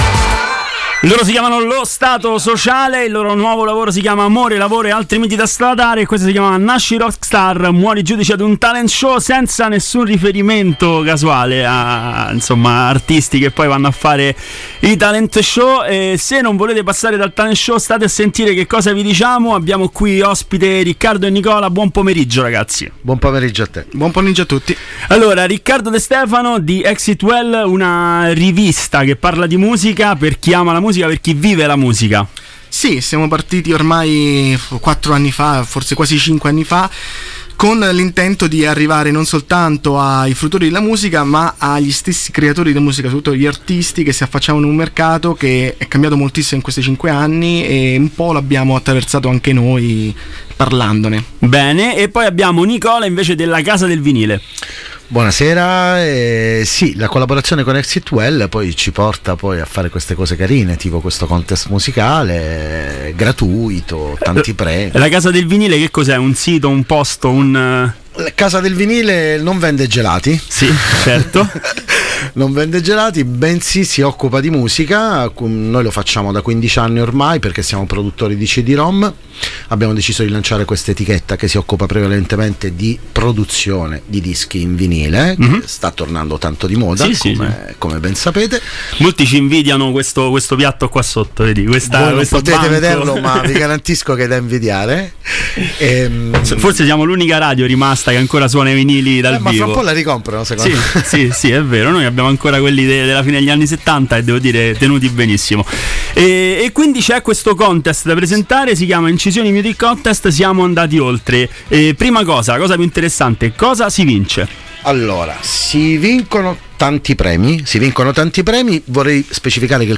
INTERVISTA INCISIONI MUSIC CONTEST | Radio Città Aperta
intervista-incisioni-music-contest.mp3